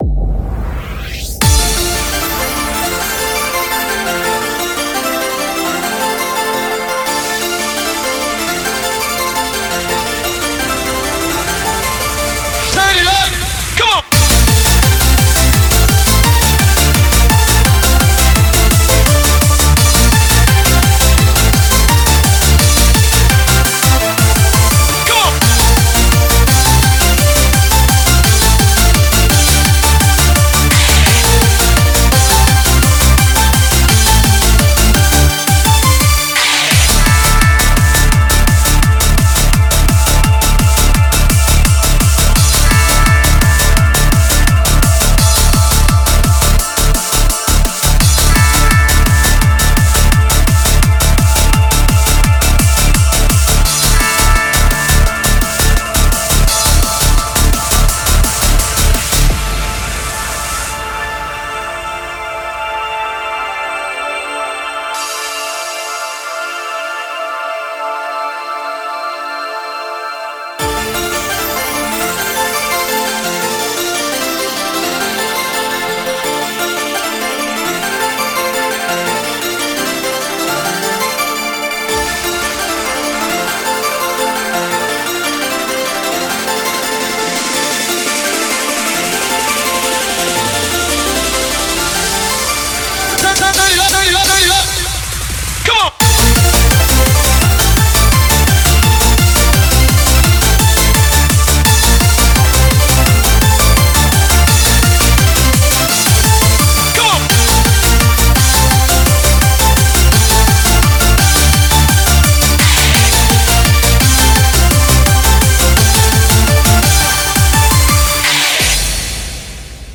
BPM170
Audio QualityLine Out